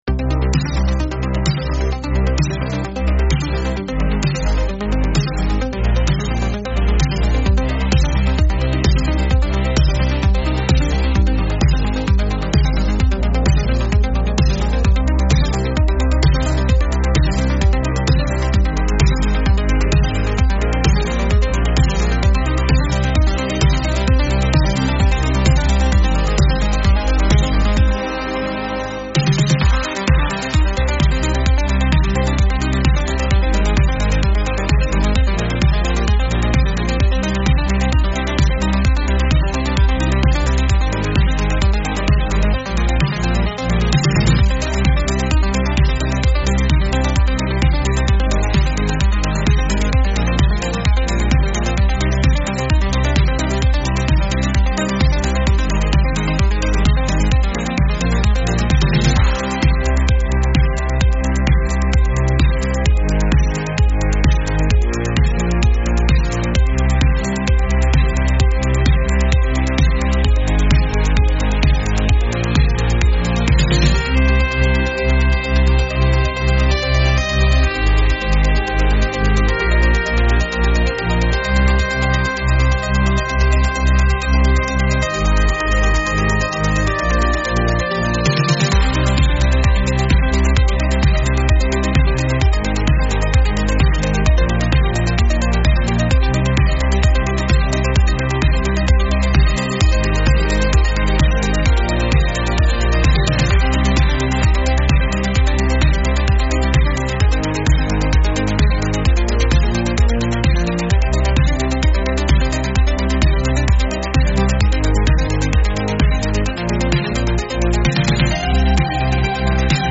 Instrumental - Real Liberty Media DOT xyz